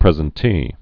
(prĕzən-tē, prĭ-zĕn-)